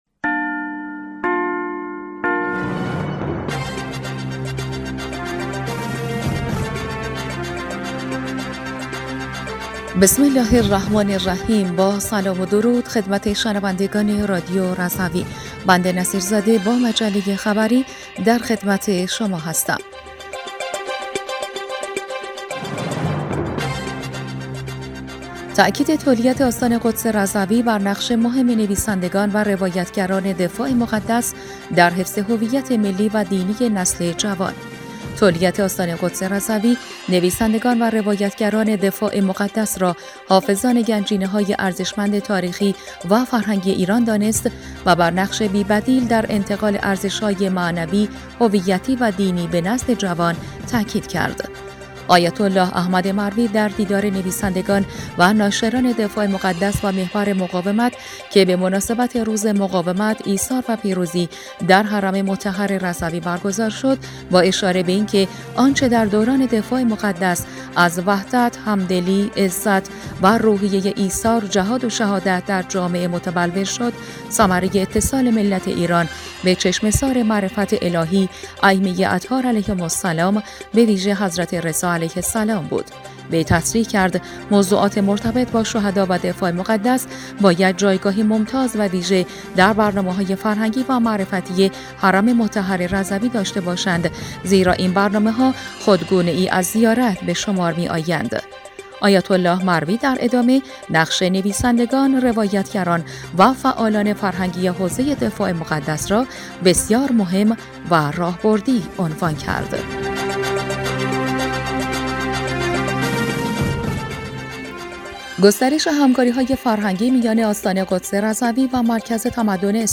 بسته خبری ۶ خرداد رادیو رضوی/